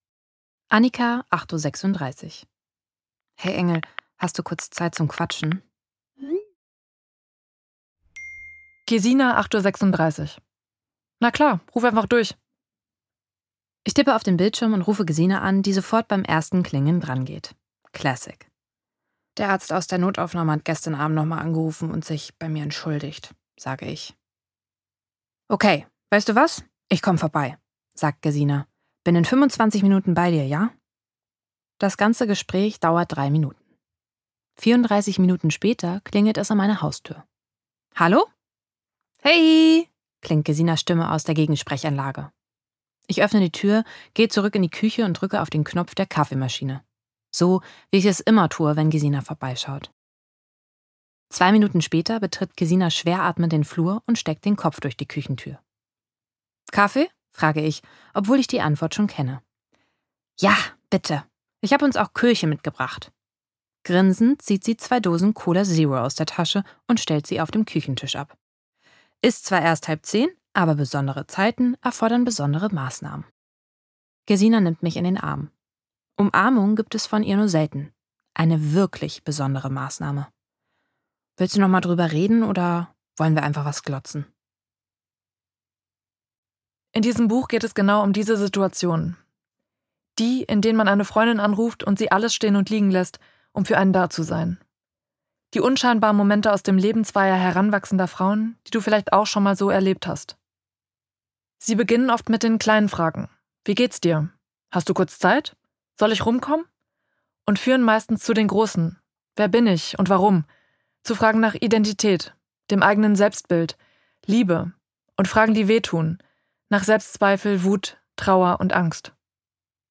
Ein tiefgründiges und witziges Hörbuch über den Weg ins Erwachsenenleben von zwei Frauen, die selbst nicht genau wissen, wo es langgeht.